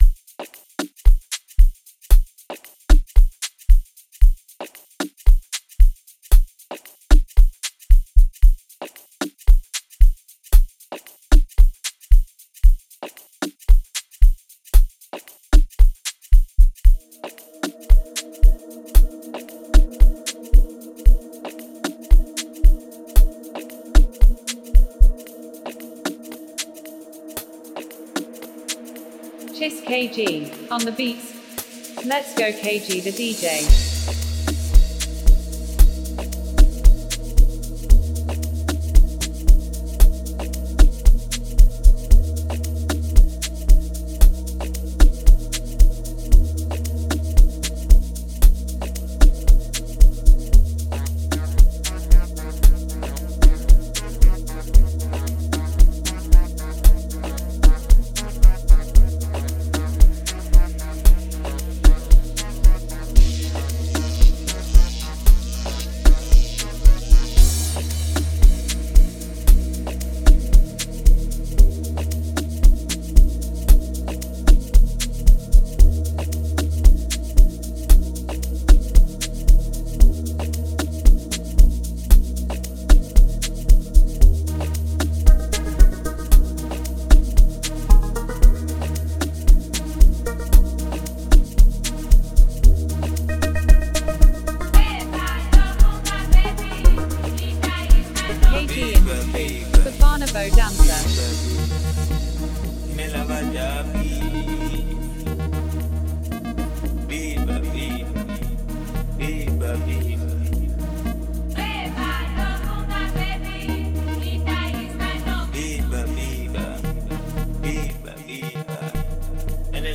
06:48 Genre : Amapiano Size